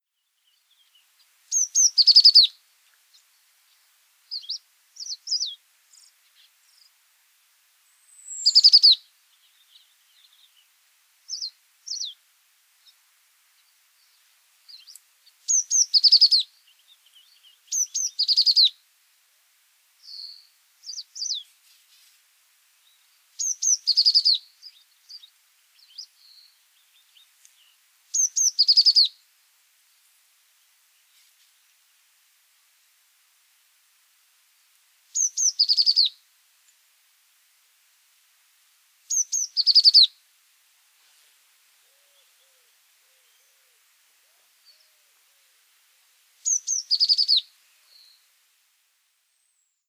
Gaišzilā zīlīte
Cyanistes cyanus